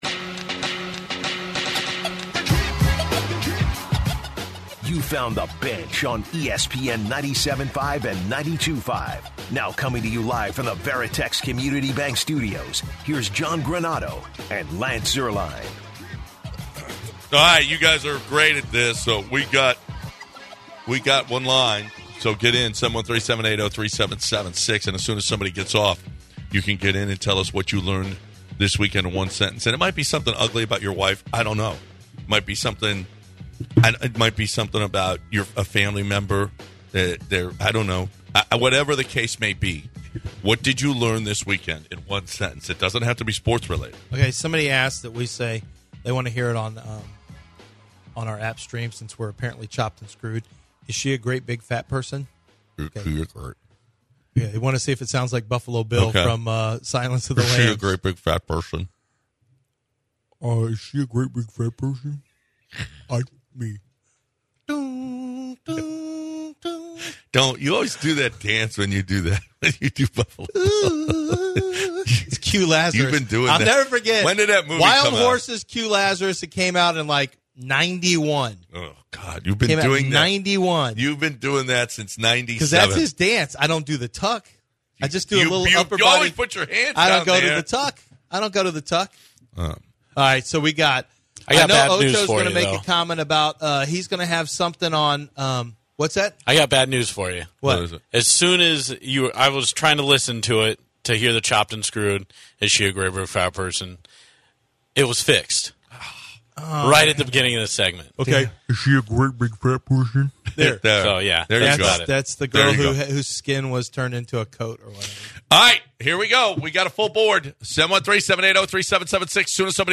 Hour 2 of the show begins with listeners calling in to share what they learned over the weekend in one sentence. The guys talk more about the Deshaun Watson trade, Carlos Correa leaving Houston for Minnesota and the Houston Cougars advancing to the Sweet Sixteen in the NCAA To...